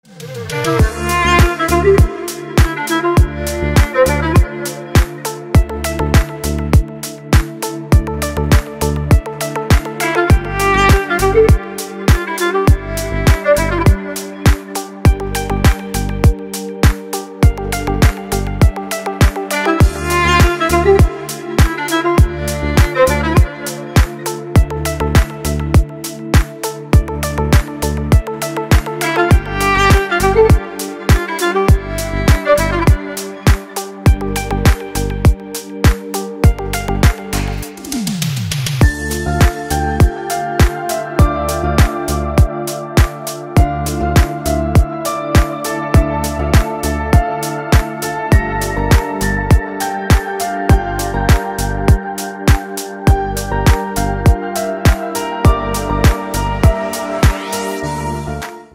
музыка без слов на вызов